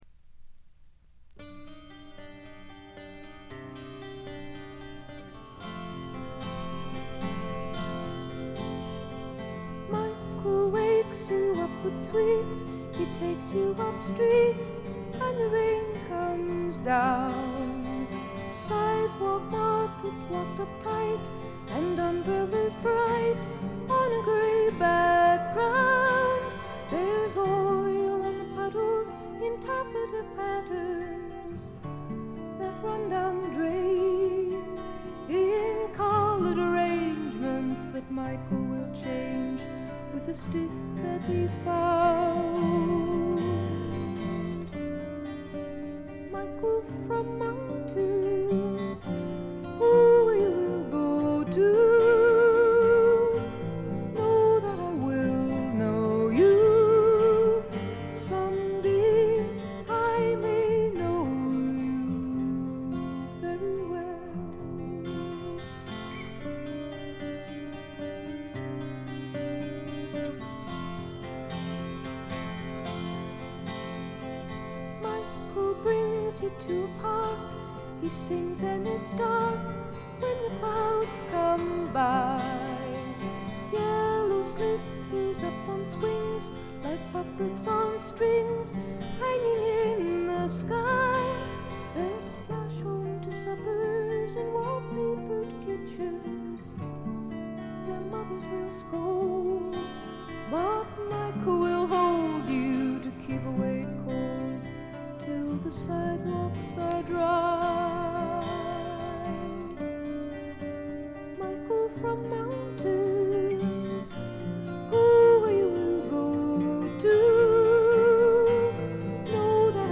プツ音が2箇所ほど出たかな？」という程度でごくわずかなノイズ感のみ。
至上の美しさをたたえたサイケ・フォーク、アシッド・フォークの超絶名盤という見方もできます。
試聴曲は現品からの取り込み音源です。
guitar, piano, vocals